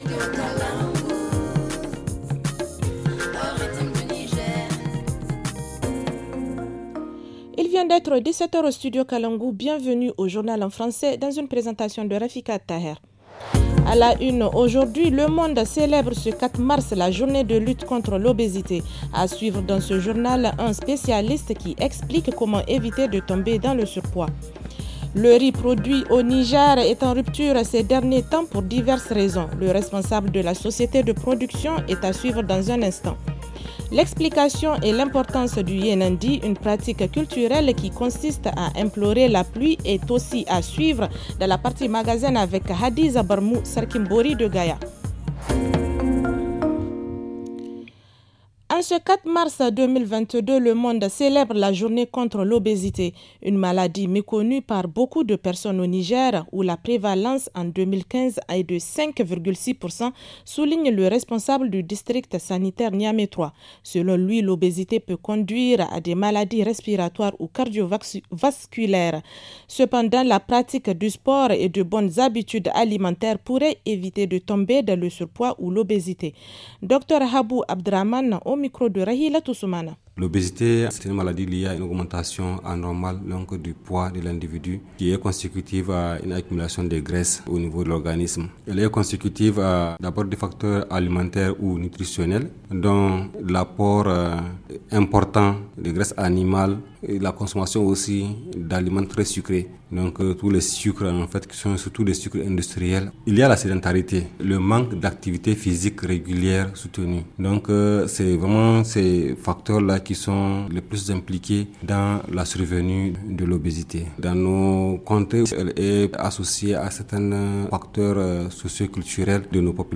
Le journal du 4 mars 2022 - Studio Kalangou - Au rythme du Niger